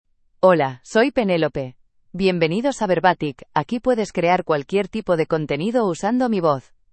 PenelopeFemale Spanish AI voice
Penelope is a female AI voice for Spanish (Spain).
Voice sample
Listen to Penelope's female Spanish voice.
Female
Penelope delivers clear pronunciation with authentic Spain Spanish intonation, making your content sound professionally produced.